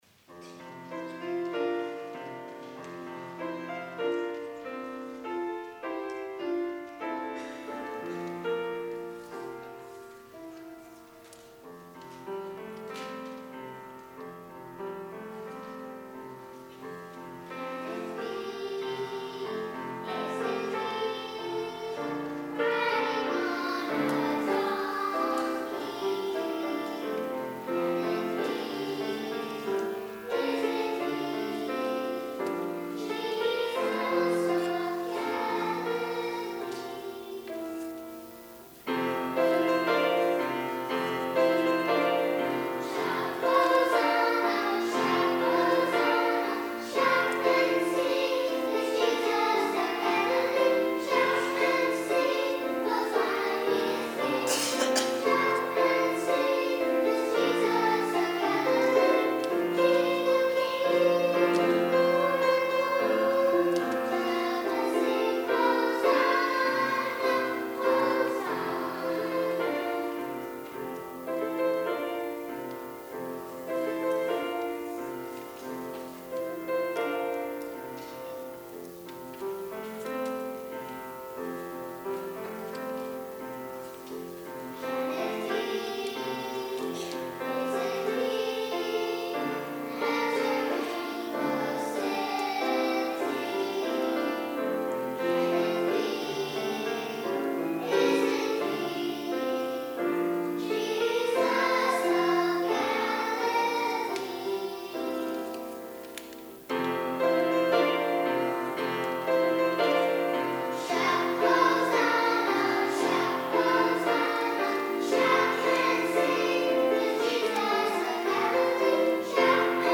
Junior Choir
piano